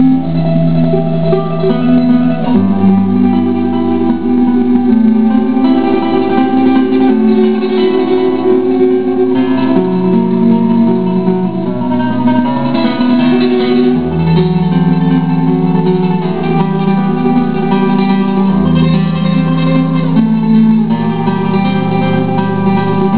Guitar Techniques
Tremolo
This means play the low E string with your thumb and then the high E string three times in rapid succesion, using the ring, middle and index fingers respectively.
tremolo.wav